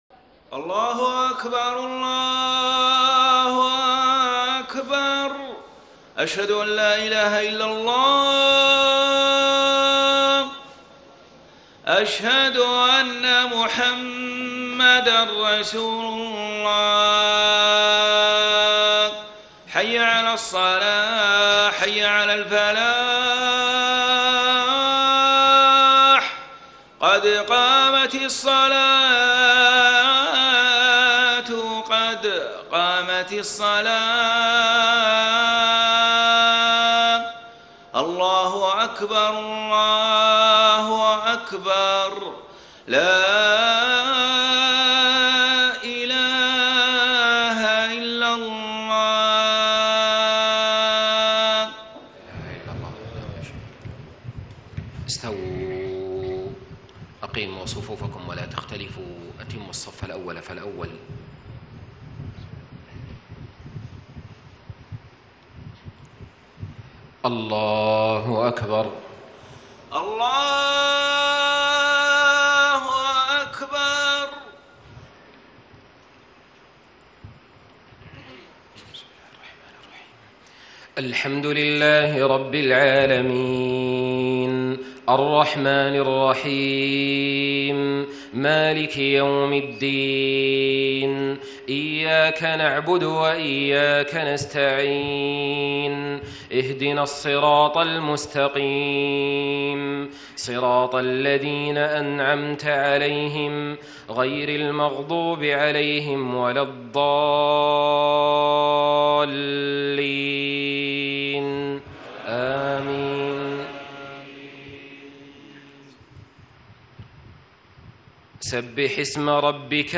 صلاة الجمعه 8 شعبان 1435 سورة الاعلى والغاشية > 1435 🕋 > الفروض - تلاوات الحرمين